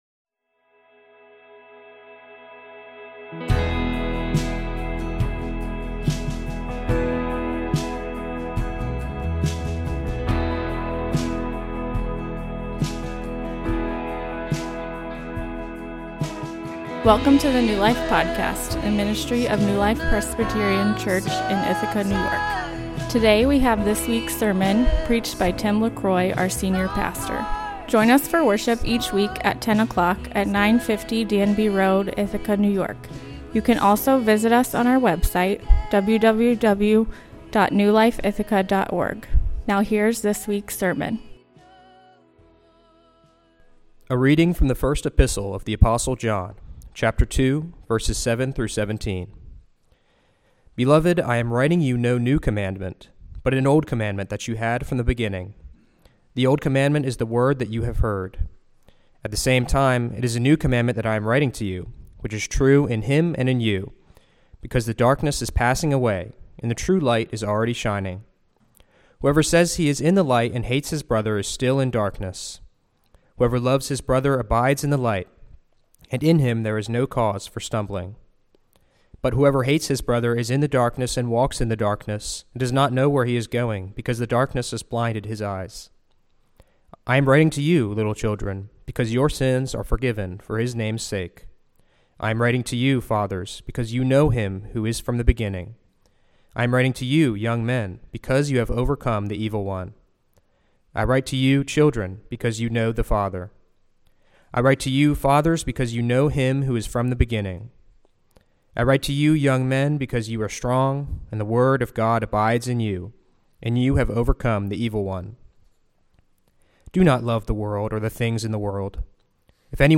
A sermon on 1 John 2:7-17 Sermon Outline: I. You know this II.